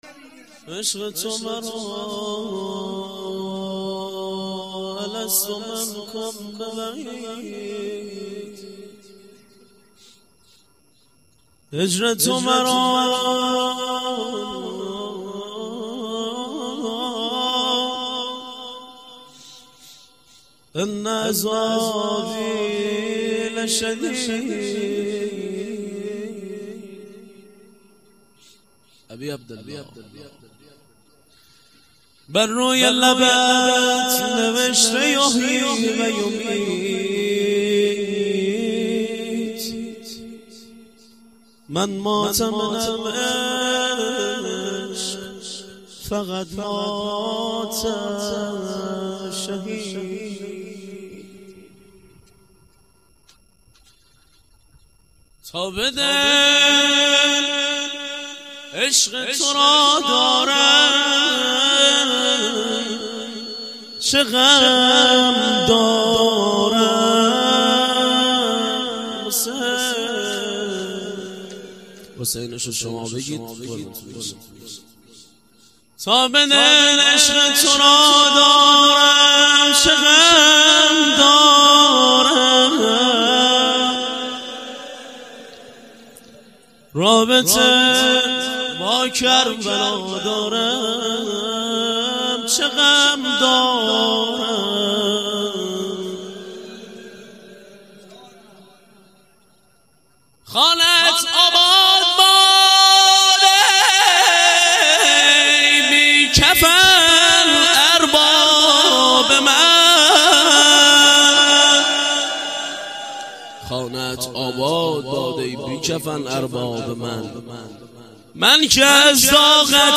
روضه پایانی - هجر تو مرا